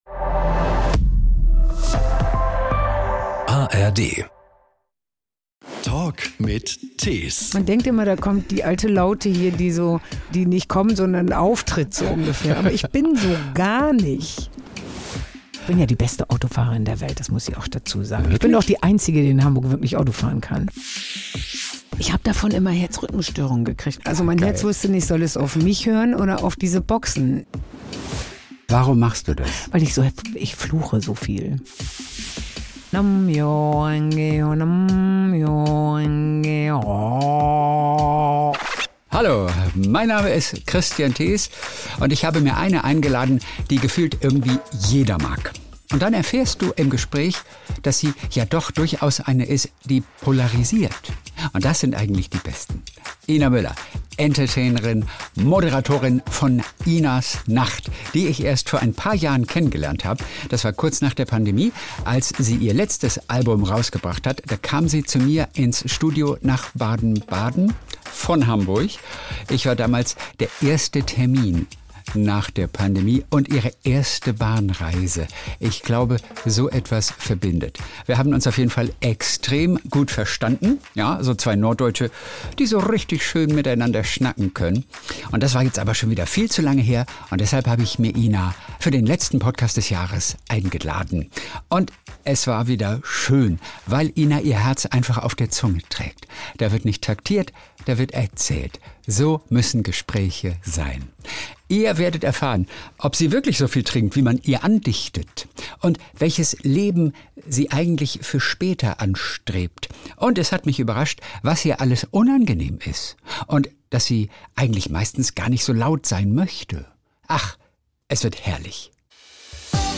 In diesem Gespräch entdecken wir eine ganz neue Ina.